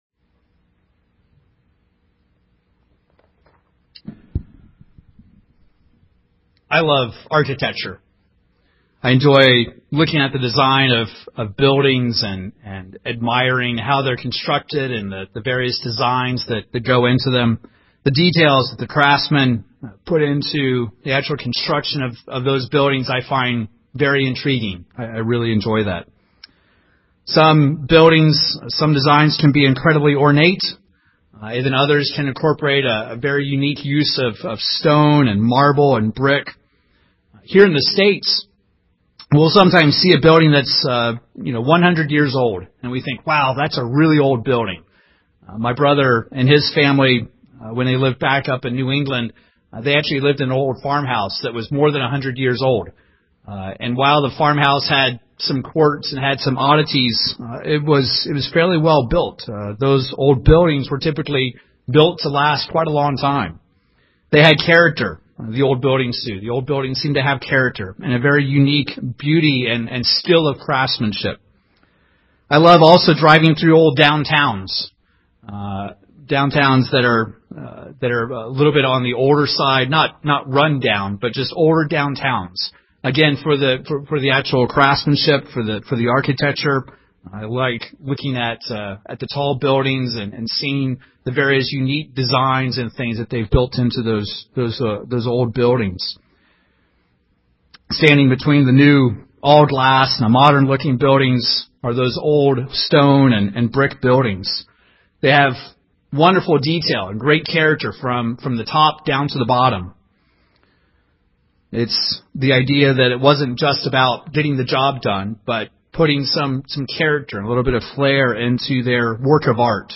Given in Wichita, KS
UCG Sermon Studying the bible?